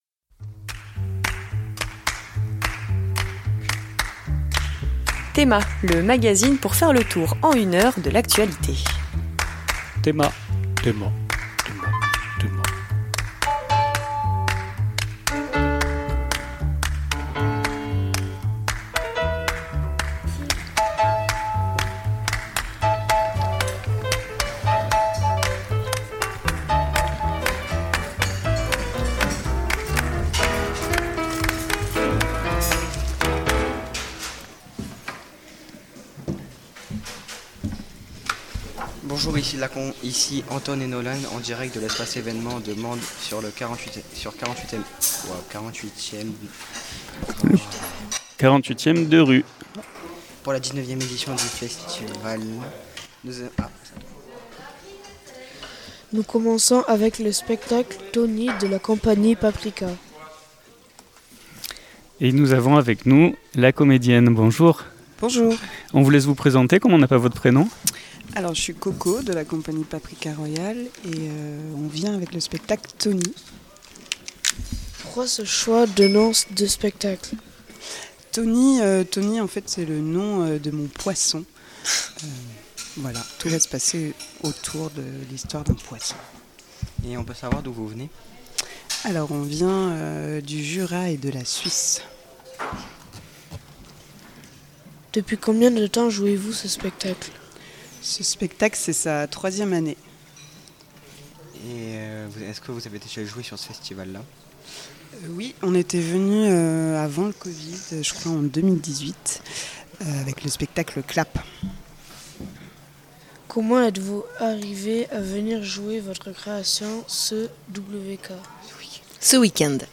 Emission du samedi 5 juillet 2025 en direct de l’espace événement Georges Frêche